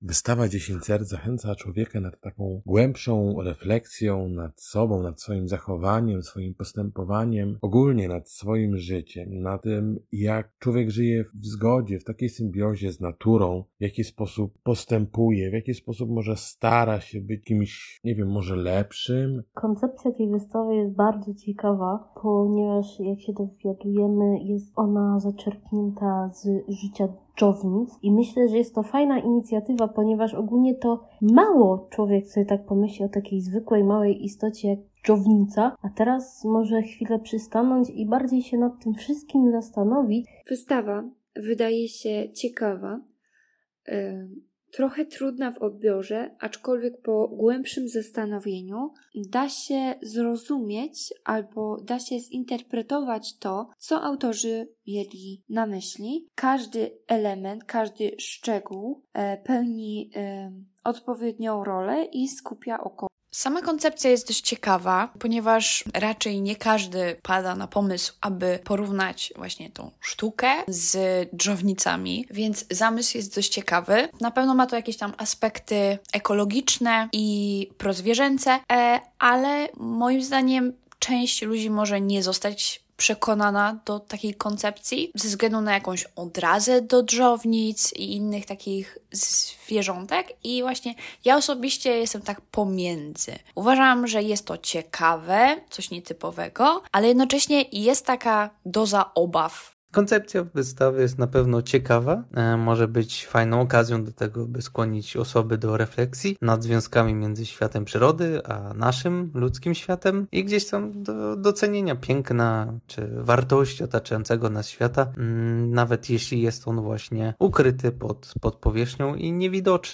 Zapytaliśmy studentów, co sądzą o tej koncepcji: